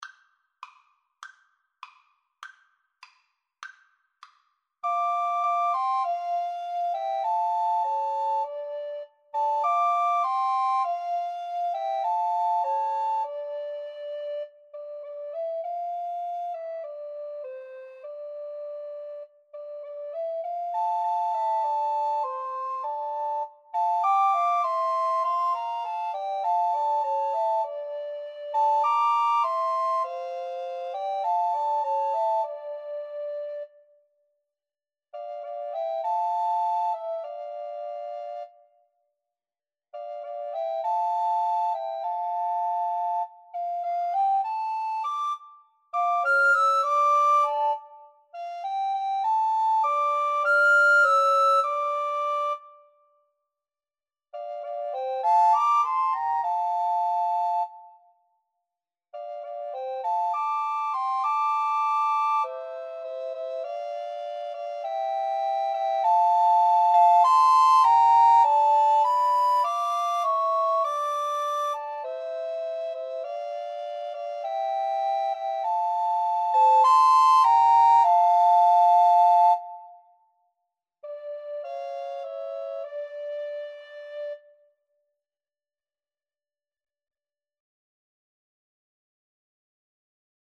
D minor (Sounding Pitch) (View more D minor Music for Recorder Trio )
Moderato
Recorder Trio  (View more Intermediate Recorder Trio Music)
Classical (View more Classical Recorder Trio Music)